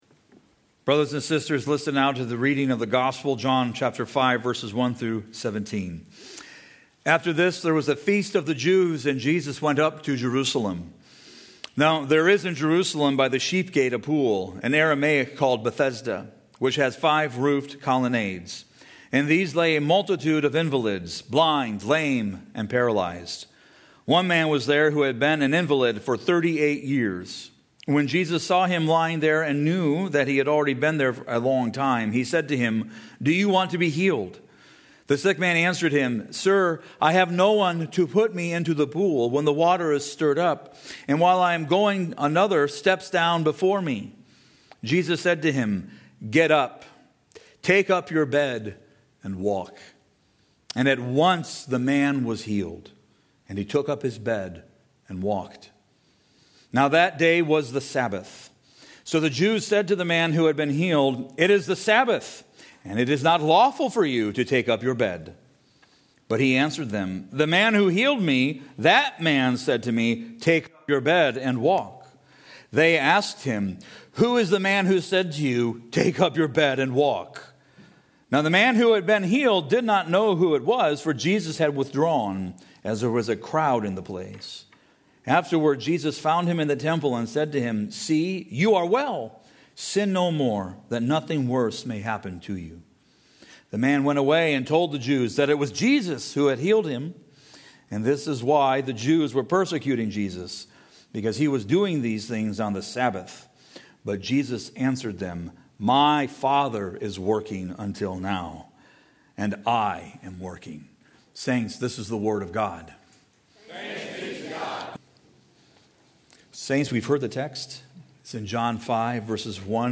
preaches on the healing of the lame man.